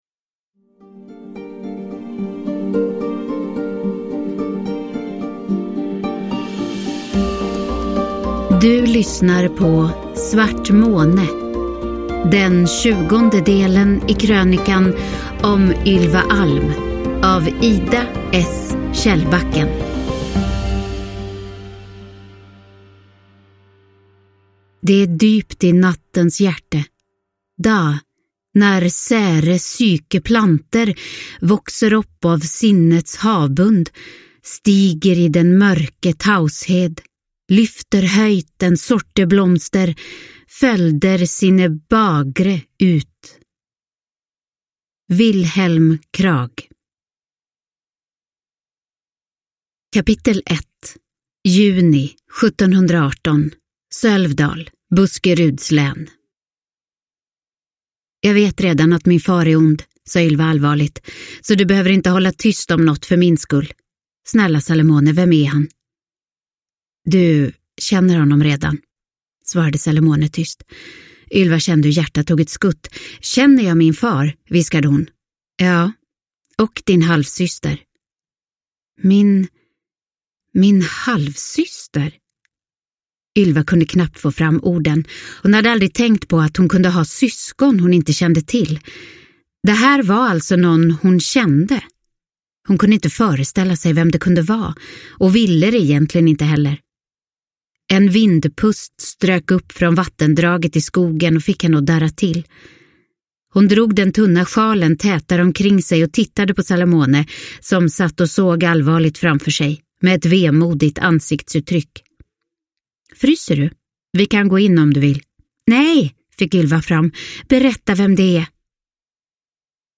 Svart måne – Ljudbok – Laddas ner